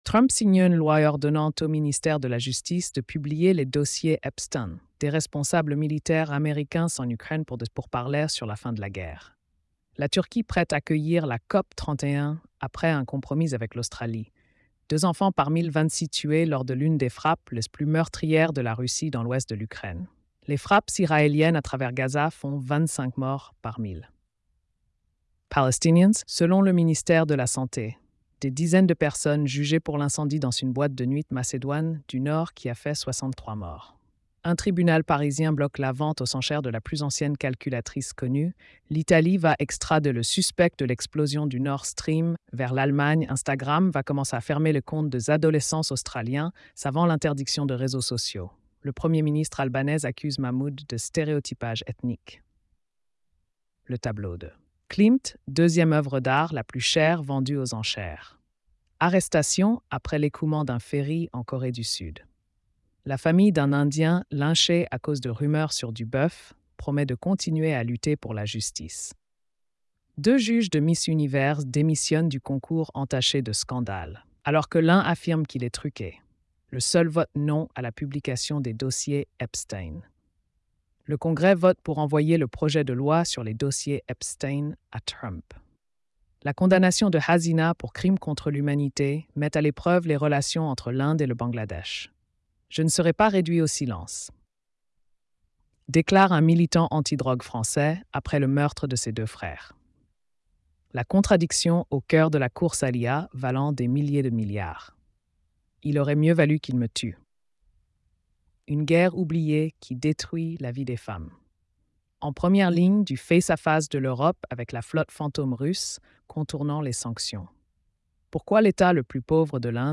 🎧 Résumé des nouvelles quotidiennes.